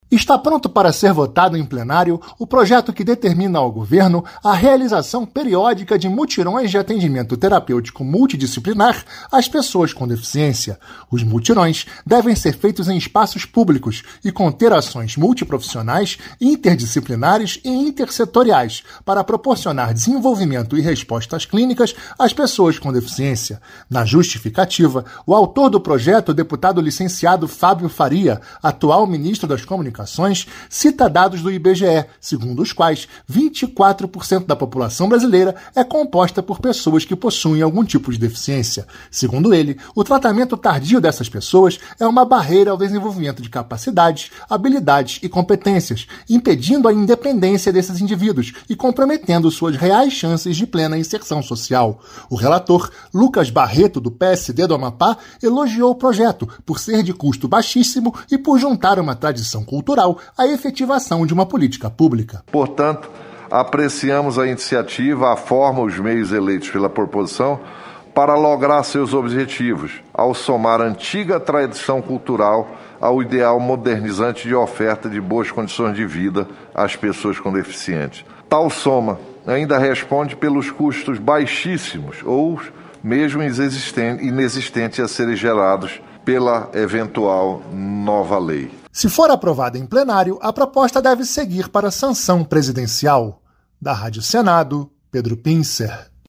RadioAgência